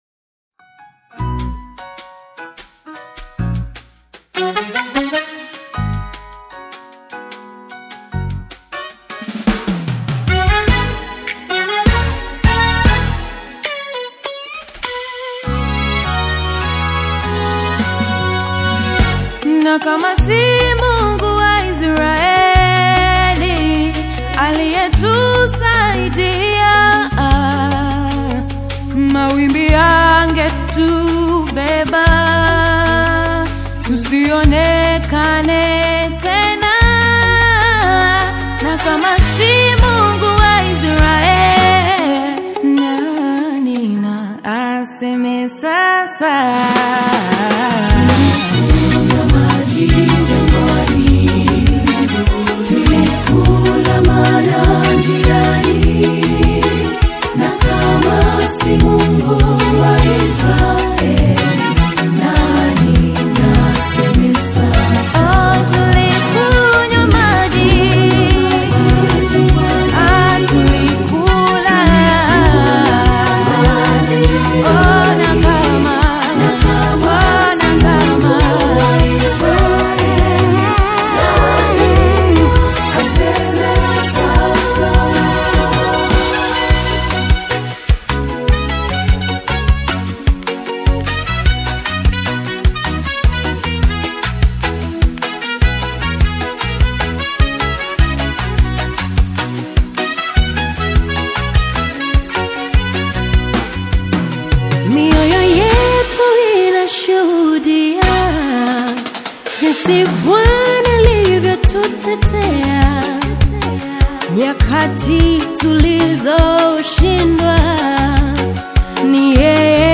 The vocally powerful and spiritually fortified single
Dar es Salaam-based Seventh-day Adventist group
It is a sharp, protective, and triumphant anthem.